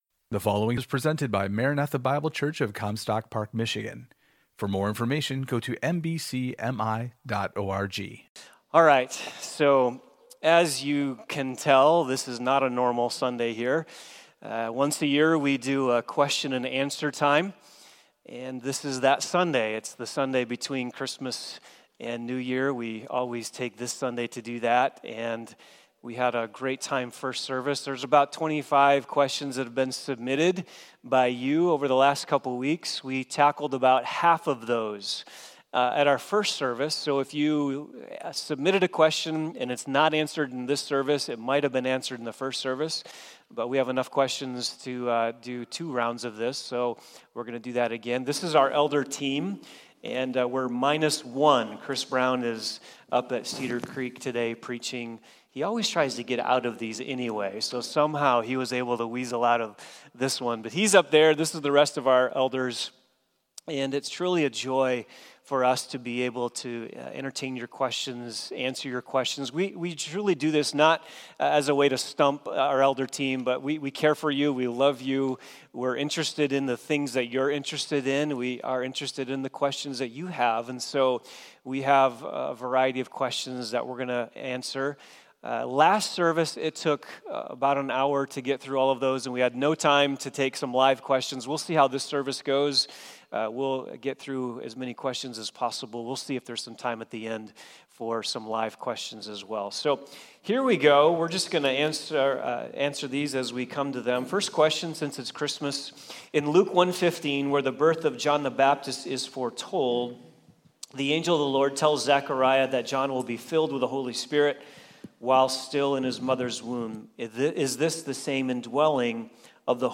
Elder Q & A – Session 2
Question and Answer